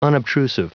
Prononciation du mot unobtrusive en anglais (fichier audio)
unobtrusive.wav